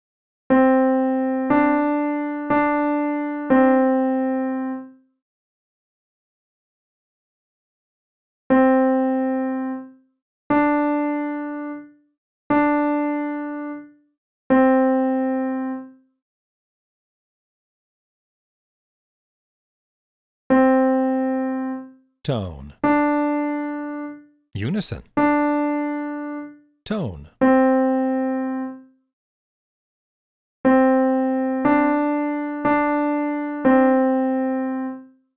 2. If you need a hint, listen to the hint clip which will play the melody more slowly and then reveal the intervals between each pair of notes.
Key: C Major, Starting Note: C
Easy_Melody_Intervals_2.mp3